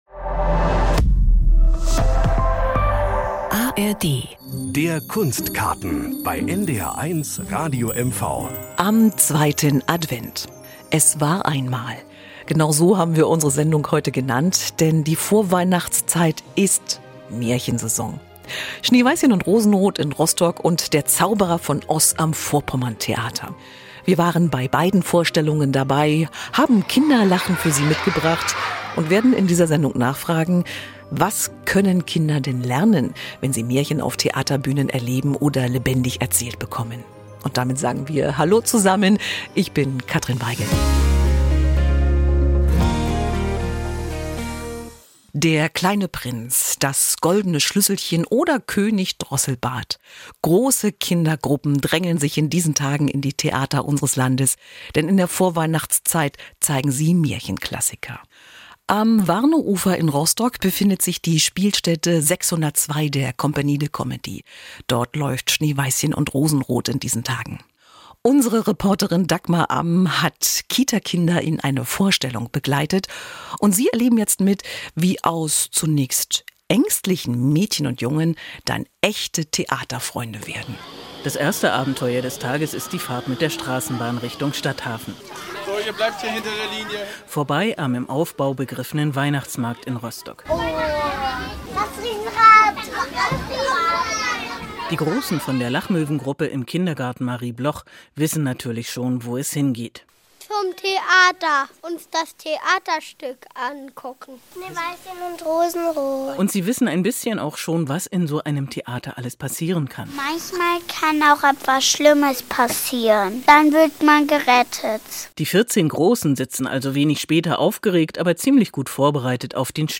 Wir waren auch zu Gast bei Vorstellungen in Rostock, Greifswald und Neustrelitz.